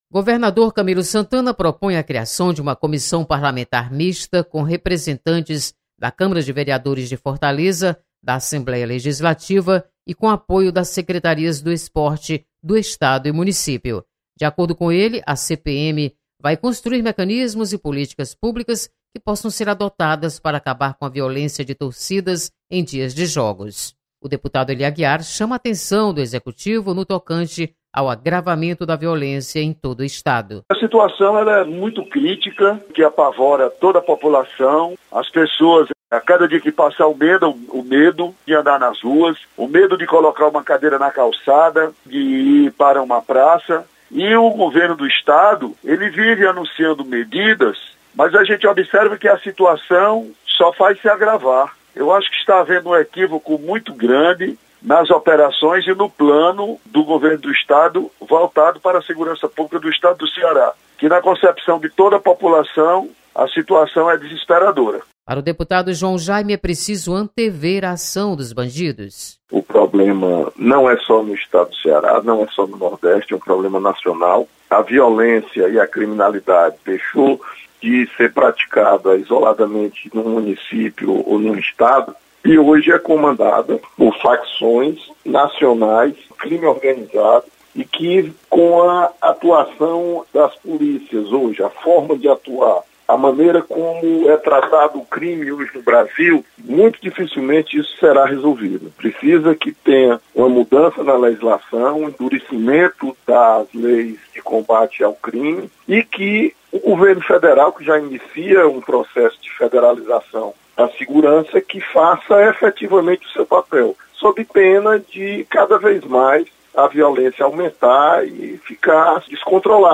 Você está aqui: Início Comunicação Rádio FM Assembleia Notícias Chacina